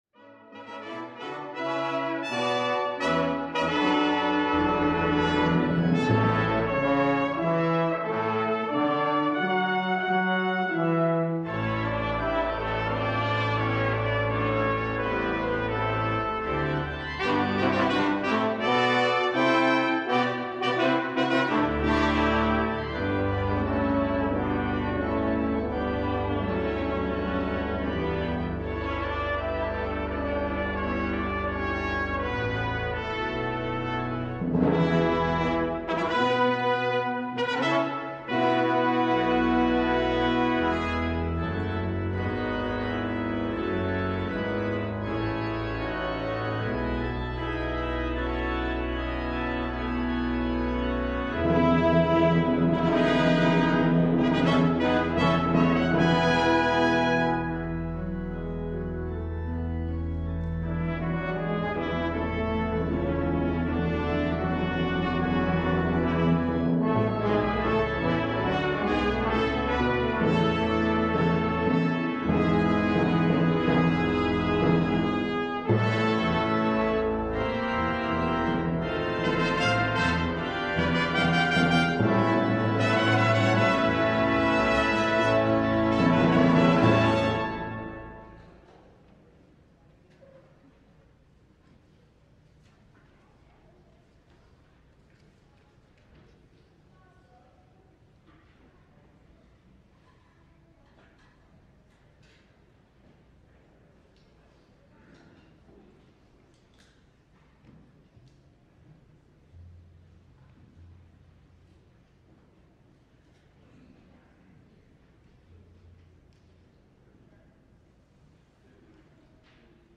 LIVE Evening Worship Service - Easter Morning Worship The Two Enemies of Easter
There will be brass and timpani and the choir will sing.